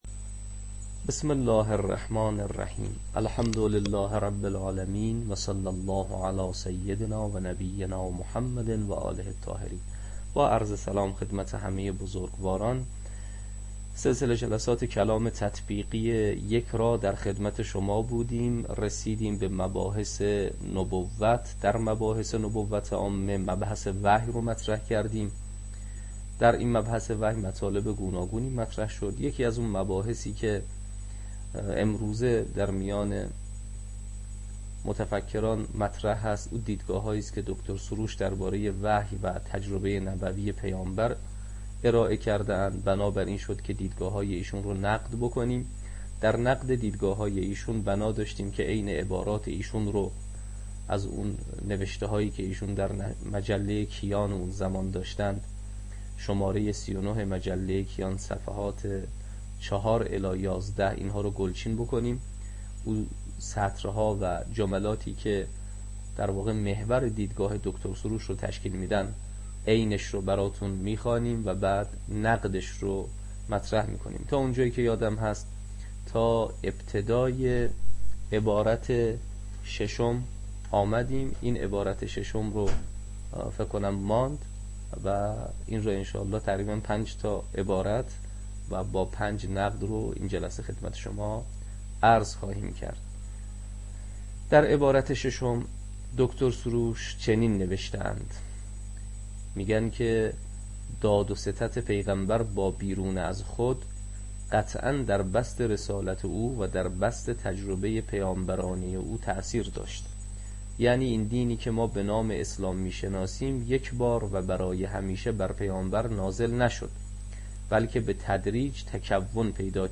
تدریس کلام تطبیقی